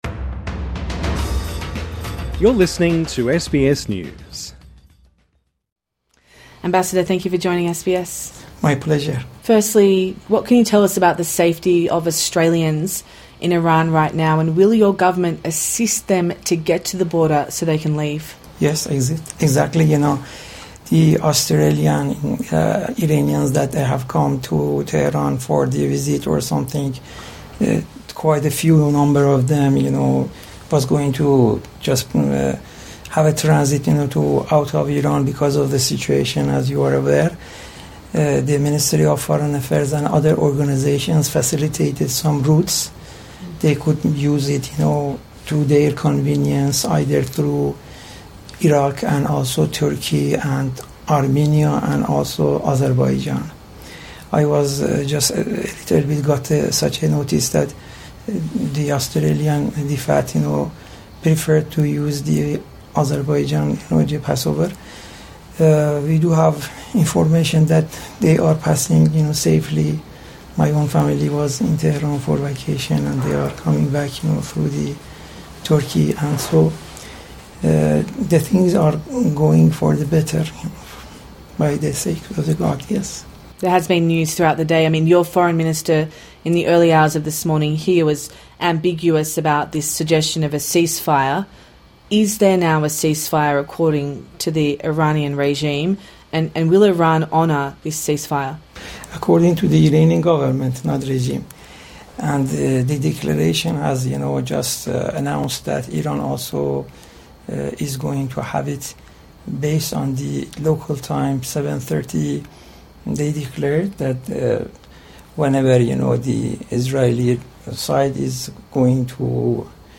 INTERVIEW: Iran's ambassador to Australia speaks to SBS